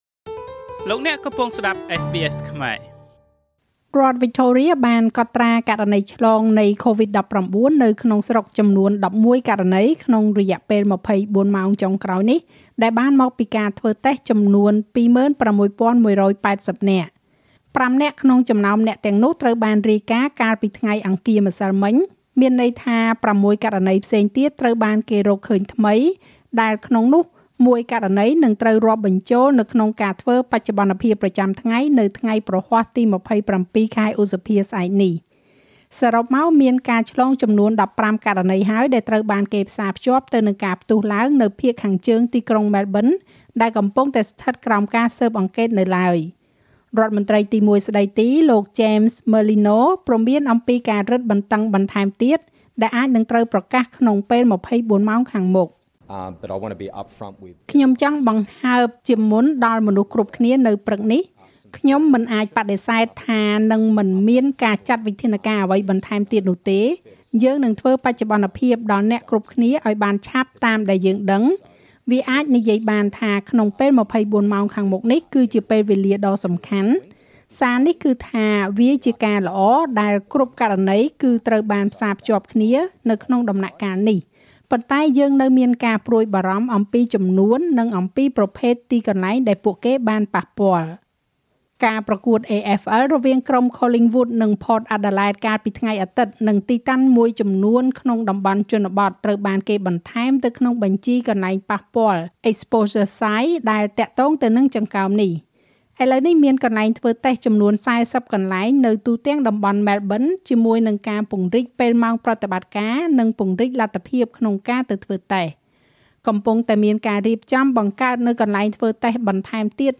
នាទីព័ត៌មានរបស់SBSខ្មែរ សម្រាប់ថ្ងៃពុធ ទី២៦ ខែឧសភា ឆ្នាំ២០២១